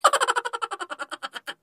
Chipmunk Laugh
Category: Sound FX   Right: Personal